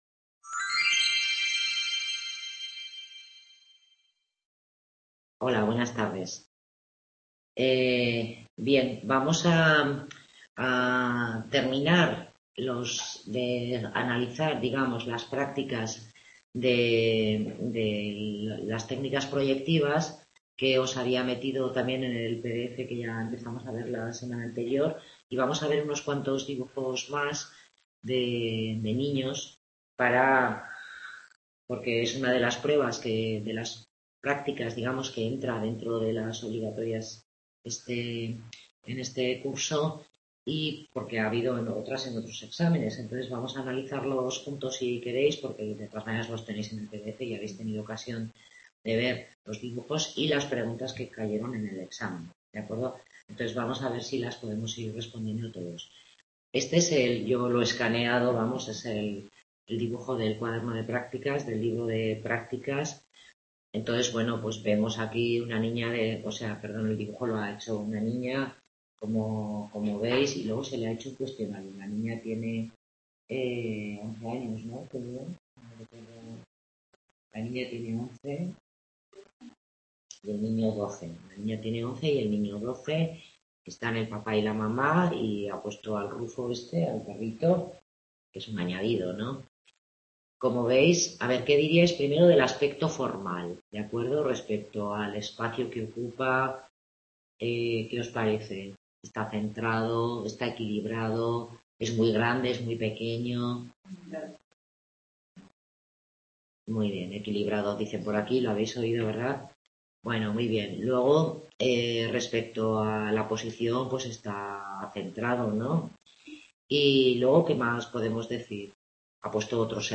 Tutoría grupal sobre las técnicas objetivas de evaluación psicológica